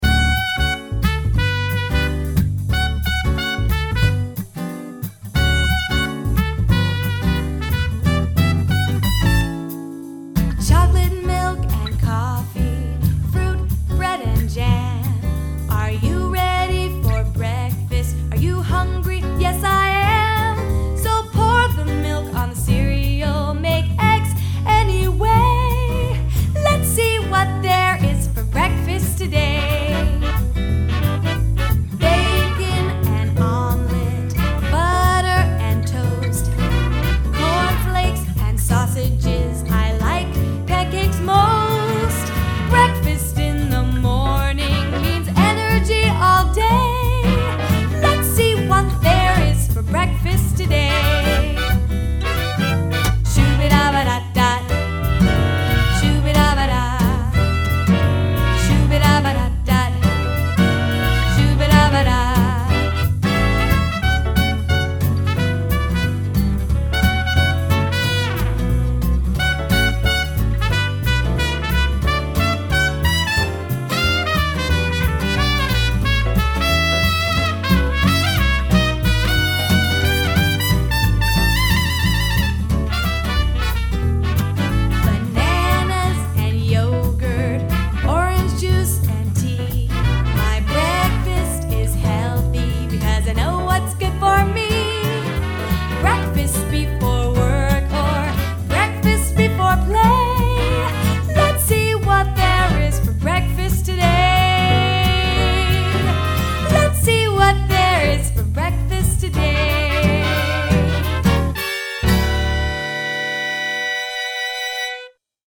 style: swing